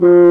Index of /90_sSampleCDs/Roland LCDP12 Solo Brass/BRS_French Horn/BRS_Mute-Stopped